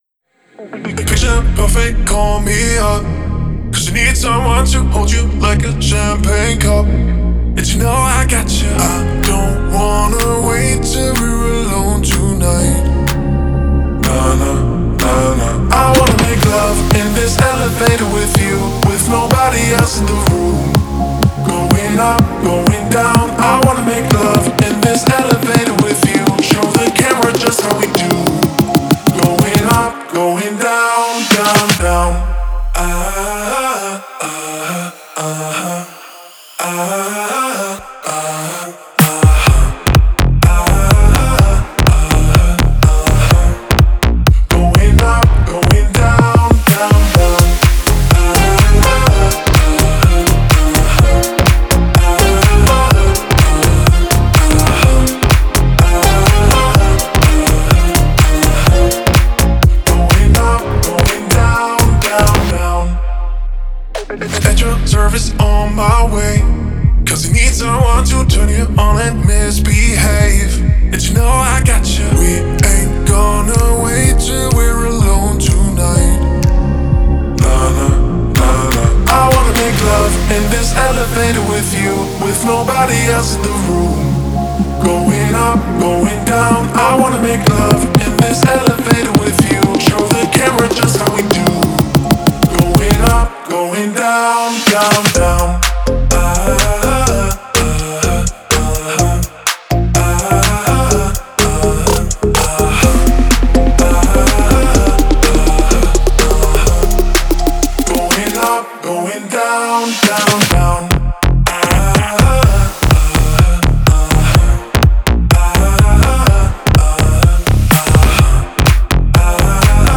энергичная танцевальная композиция